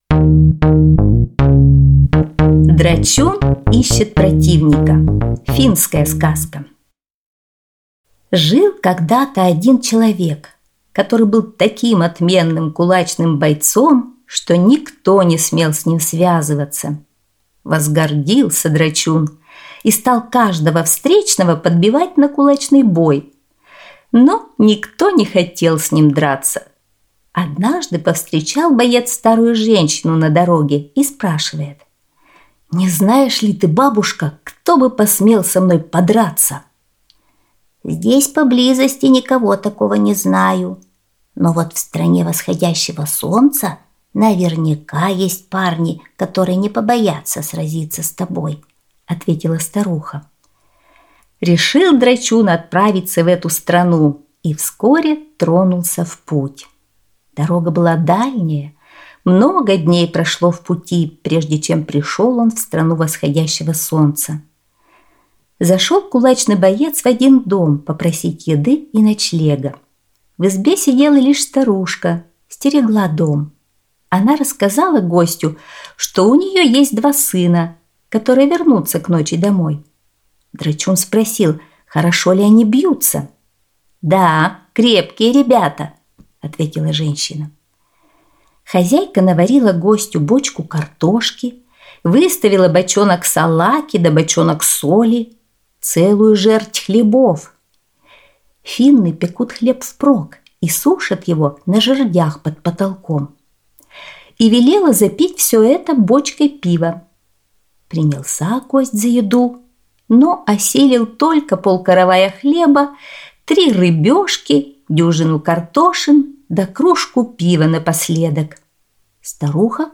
Аудиосказка «Драчун ищет противника»